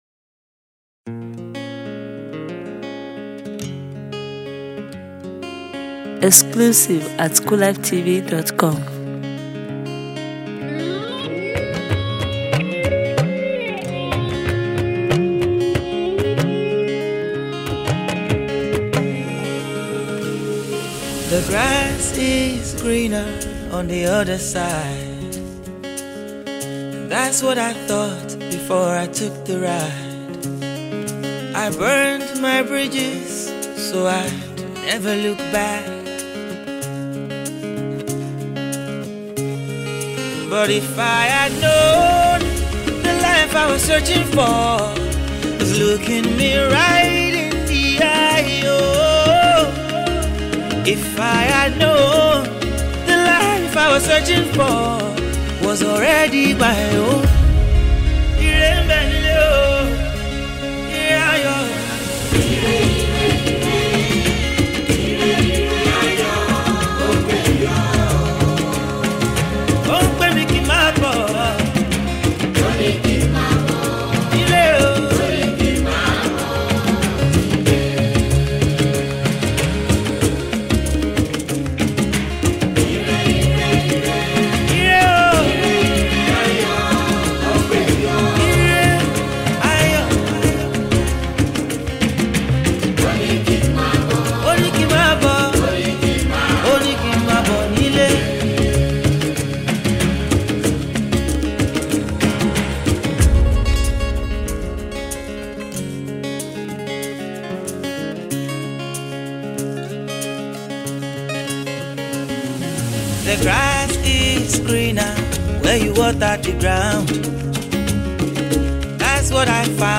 Soul/Afro Pop single